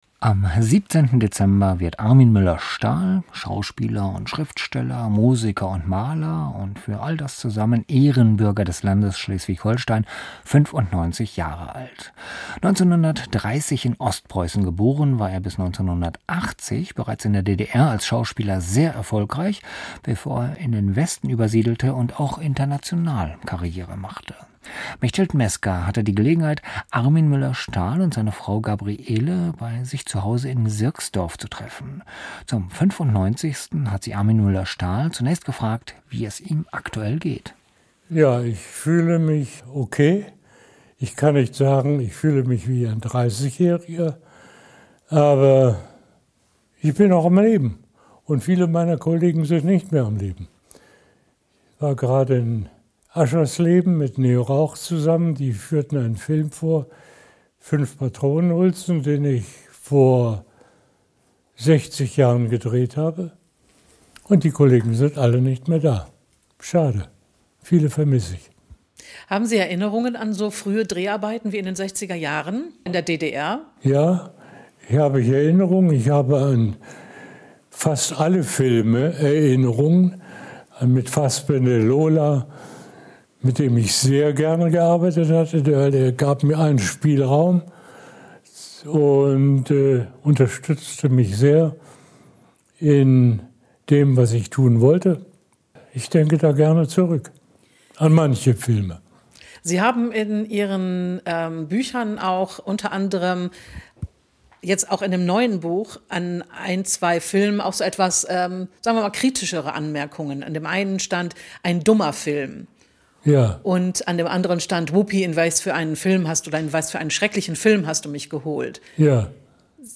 Armin Mueller-Stahl erzählt anlässlich seines 95. Geburtstags aus seinem Leben als Schauspieler, Maler und Musiker.